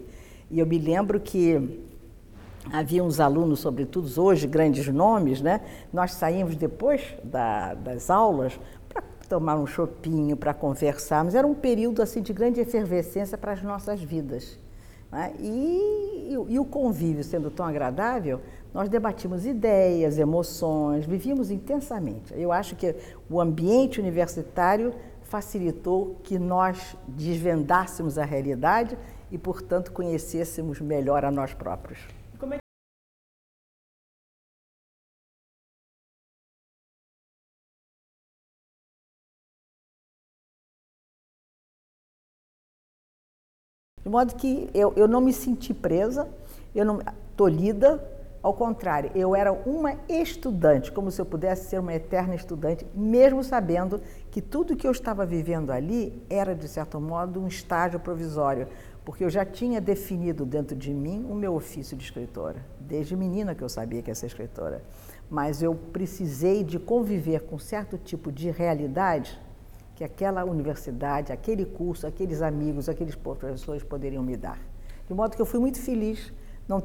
Entrevistada pela TVPUC   IDÉIAS E EMOÇÕES
Jornalista e escritora, Nélida Piñon se formou em Comunicação (56).